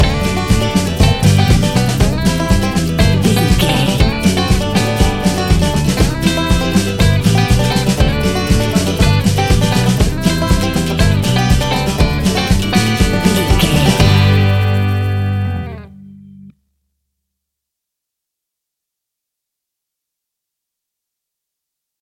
Uplifting
Ionian/Major
earthy
acoustic guitar
mandolin
ukulele
drums
double bass
accordion